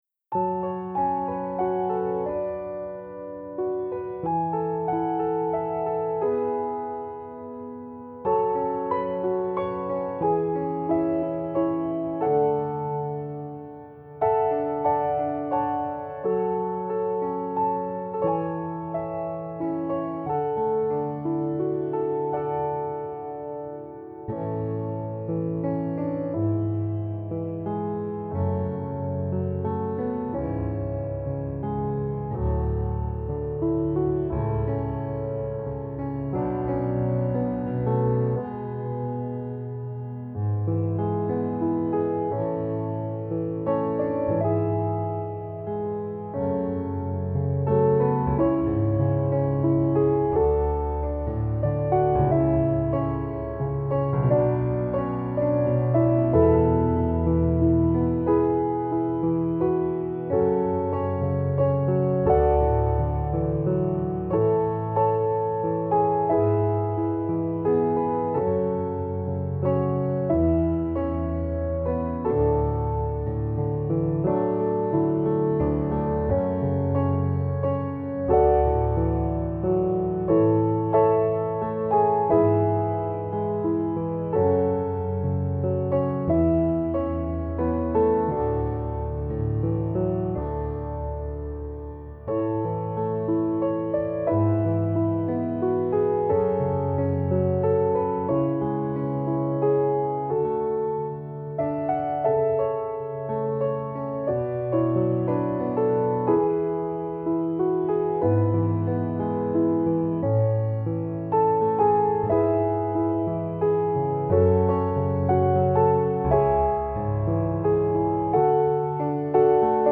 Take My Hand Solo Piano 2023
take-my-hand-solo-piano-mix-5-n.mp3